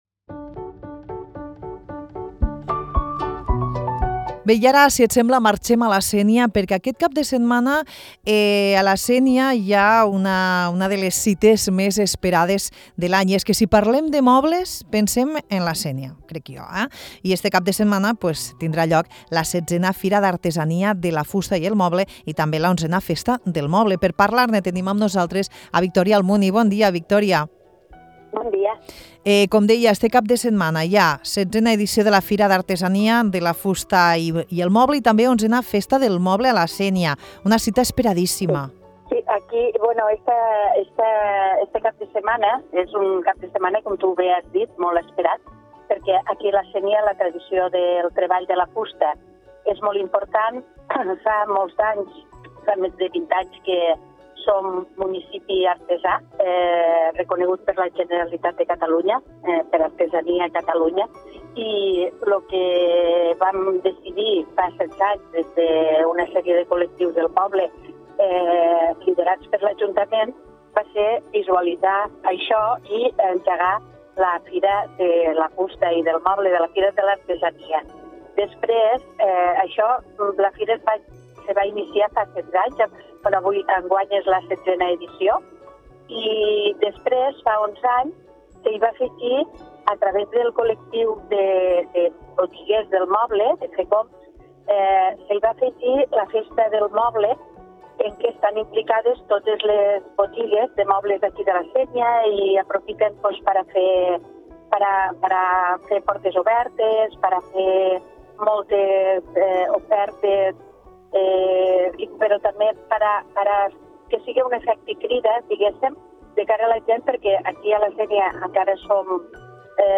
Amb l’alcaldessa de la Sénia, Victòria Almuni, xerrem sobre la XVI Fira d’Artesania de la Fusta i el Moble i la XI Festa de la Fusta, dos esdeveniments que celebren la rica tradició fustera del municipi i dels quals es podrà gaudir aquest cap de setmana.